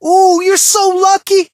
brock_death_02.ogg